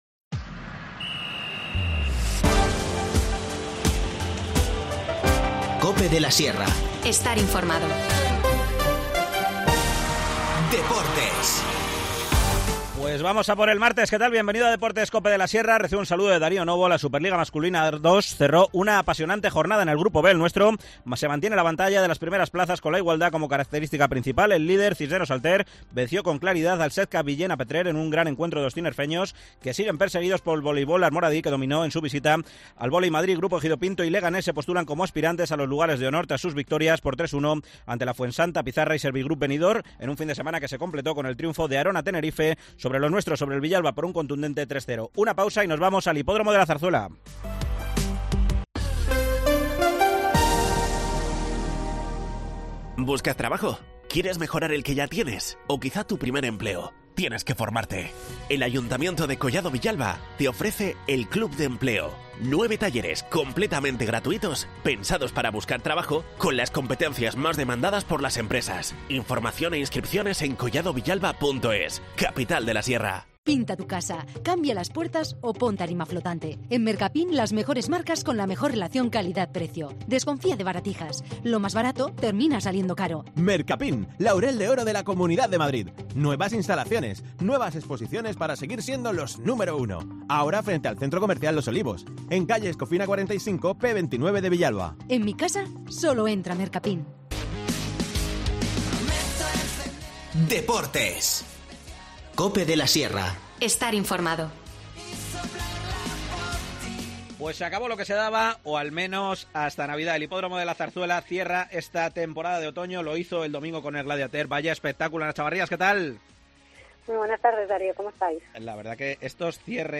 Óbano se lleva el Gladiateur, la carrera de fondo más larga del turf español. Nos lo cuenta desde el Hipódromo de La Zarzuela